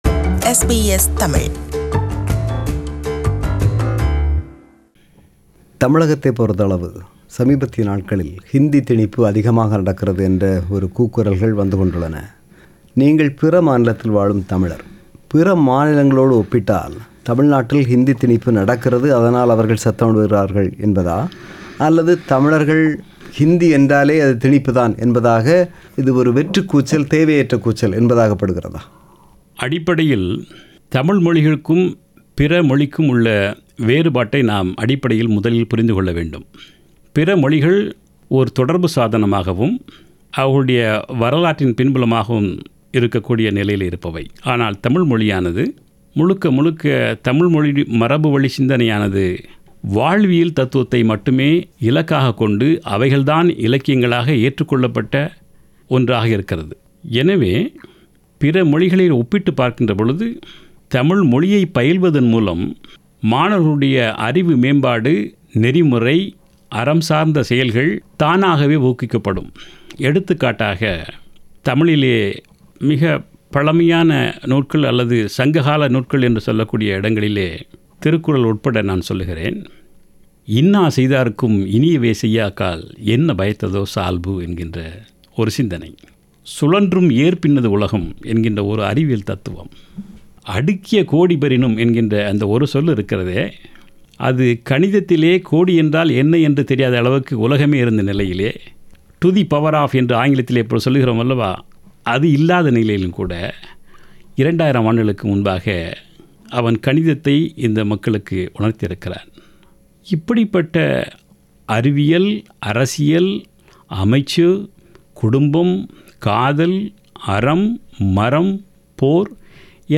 அவரை நமது சிட்னி ஒலிப்பதிவு கூடத்தில் சந்தித்து உரையாடியவர்
நேர்முகம் பாகம் 2.